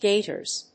/ˈgetɝz(米国英語), ˈgeɪtɜ:z(英国英語)/